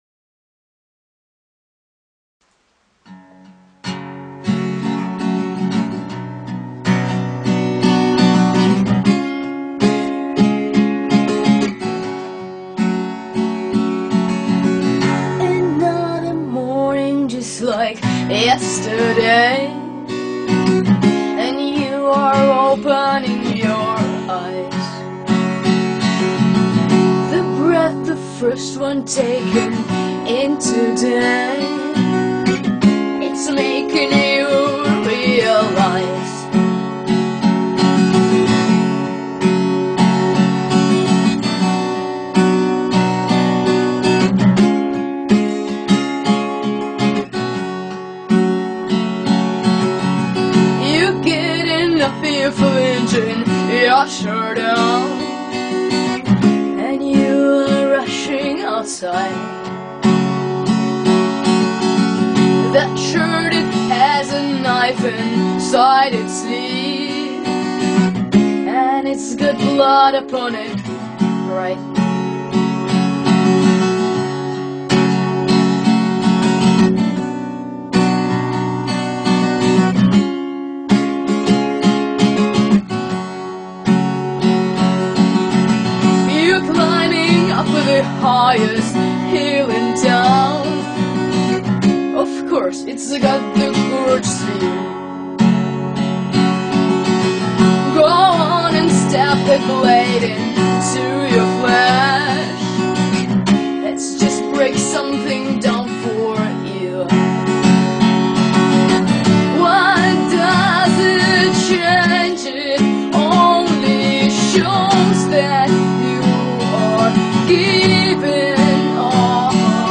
(I recorded this with my new camcorder - see/hear the sound difference to my previous vids)
Capo 2.:
Em Am B